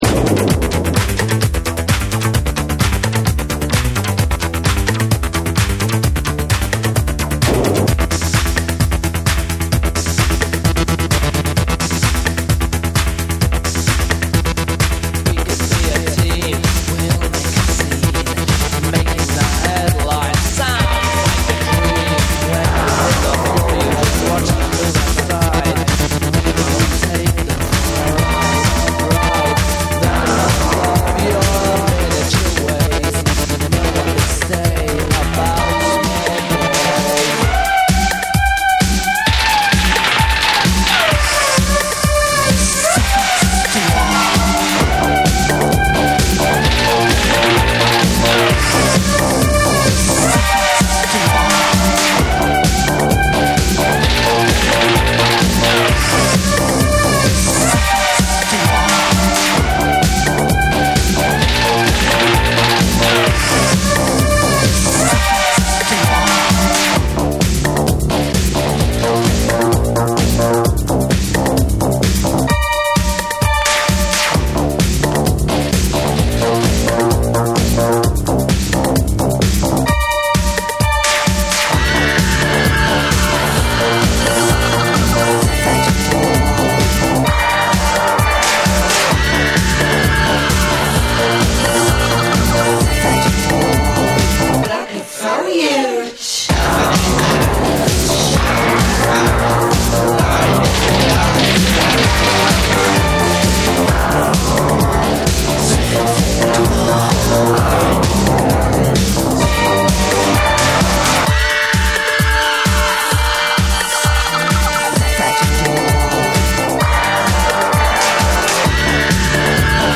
NEW WAVE & ROCK / RE-EDIT / MASH UP